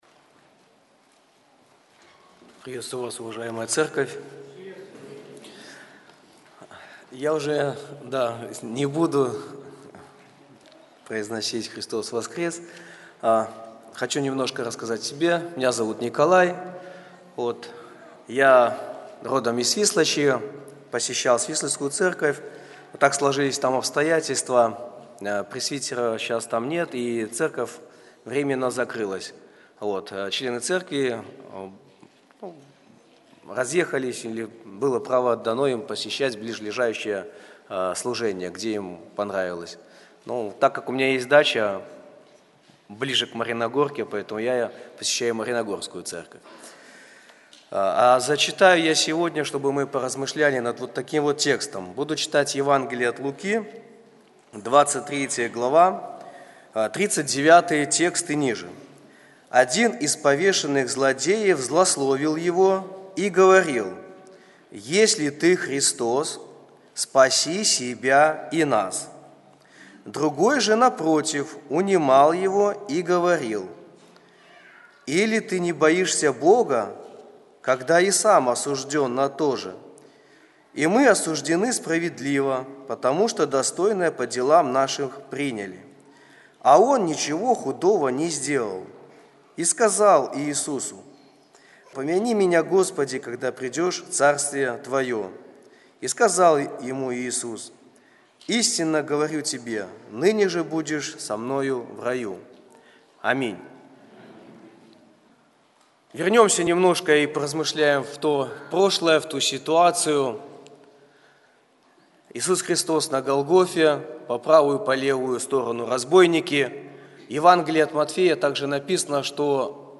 Церковь евангельских христиан баптистов в городе Слуцке